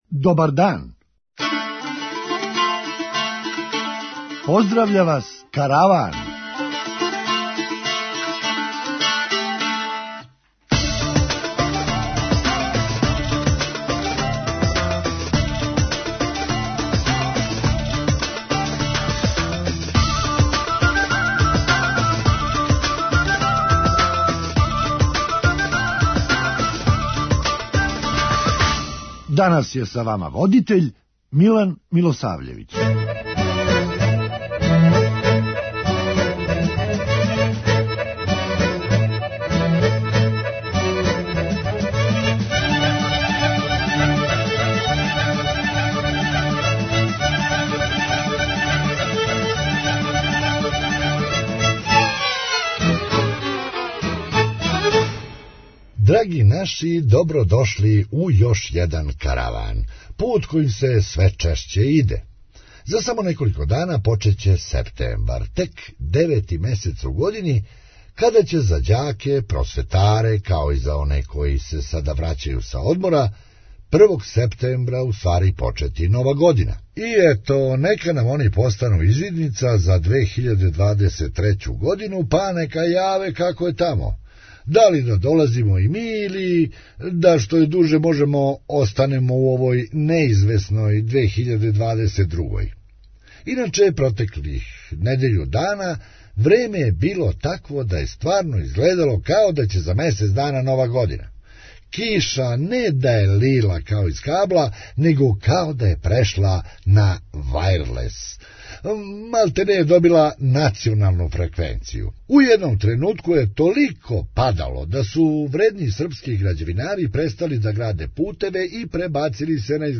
Хумористичка емисија
Тачно онолико времена колико оцу треба да им каже да су управо прошли искључење за сладолед. преузми : 8.97 MB Караван Autor: Забавна редакција Радио Бeограда 1 Караван се креће ка својој дестинацији већ више од 50 година, увек добро натоварен актуелним хумором и изворним народним песмама.